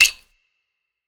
Perc 2 [ childplay ].wav